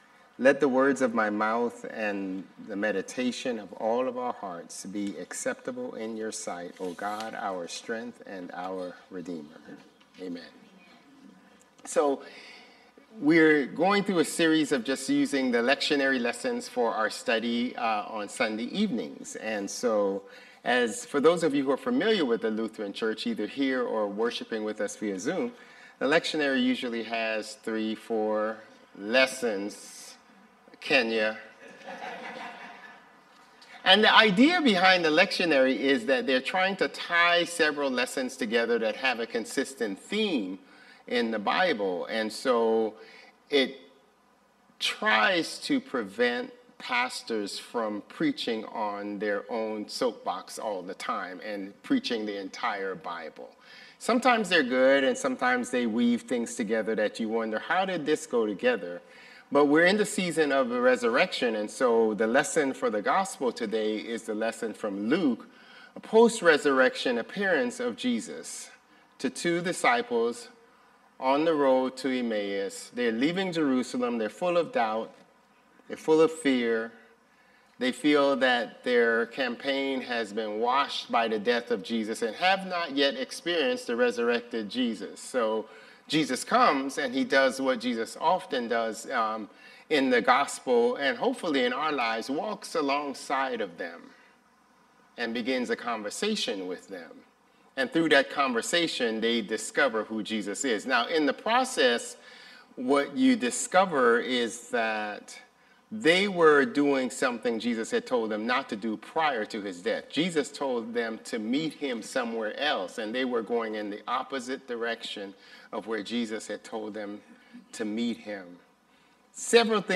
Sermons | Bethel Lutheran Church
This interactive sermon explores the post-resurrection appearance of Jesus to two disciples on the road to Emmaus (Luke 24), examining how we often fail to recognize God's presence in our everyday struggles because we're looking for spectacular miracles instead of quiet companionship.